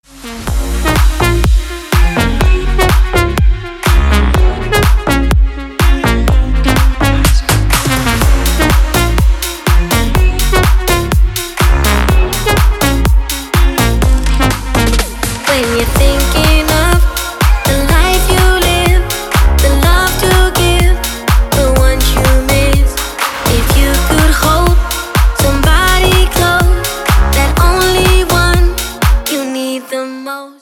Вырезки из клубных треков